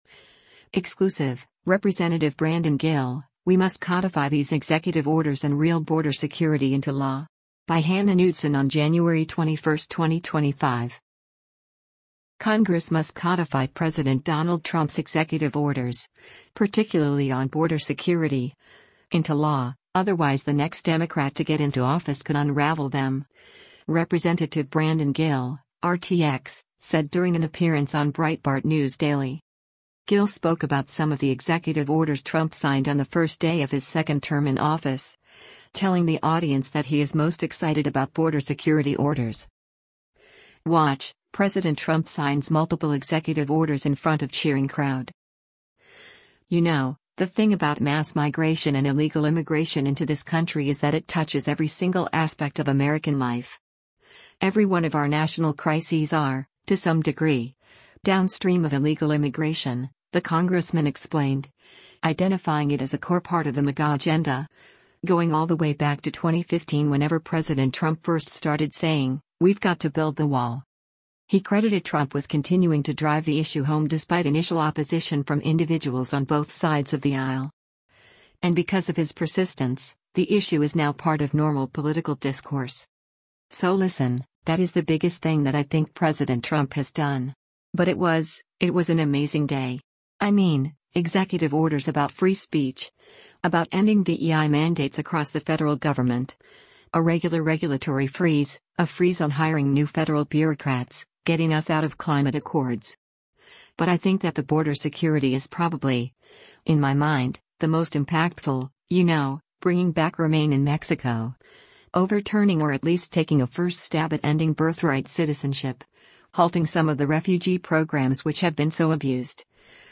Congress must codify President Donald Trump’s executive orders — particularly on border security — into law, otherwise the next Democrat to get into office could unravel them, Rep. Brandon Gill (R-TX) said during an appearance on Breitbart News Daily.
Breitbart News Daily airs on SiriusXM Patriot 125 from 6:00 a.m. to 9:00 a.m. Eastern.